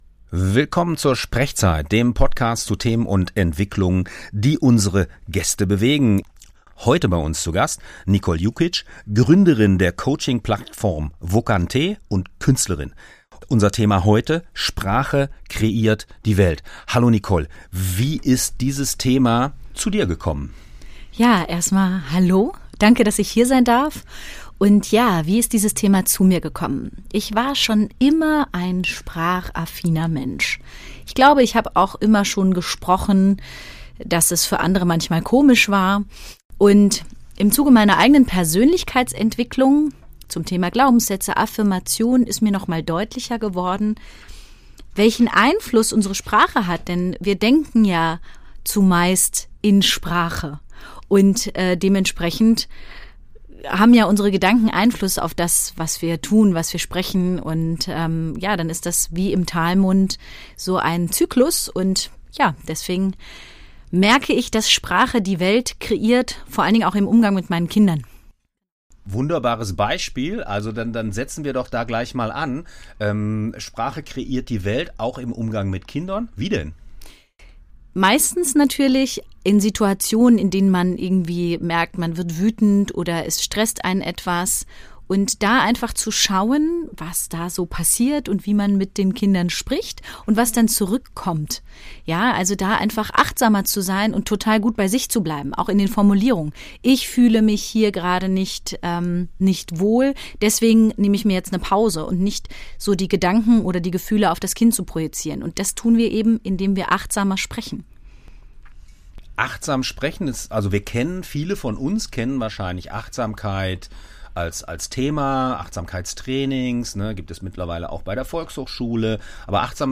Dieser SprechZeit-Podcast wurde am 2.12.2024 beim Freien Radio Kassel aufgezeichnet.